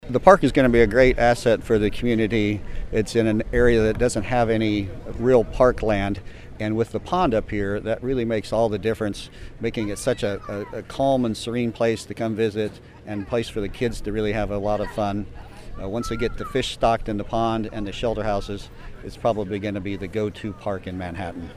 Mayor Mark Hatesohl was at the dedication, and spoke about the park.